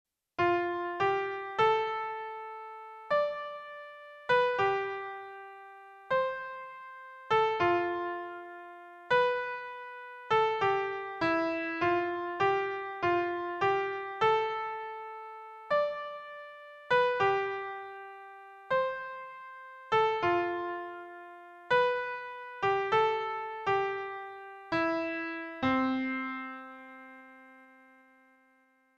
Помогите, пожалуйста, определить что это за вальс, по ниже прилагаемому наброску, примерно наигранному по памяти.
(Среднего темпа, как примерно у меня и наиграно выше.
unknown_waltz.mp3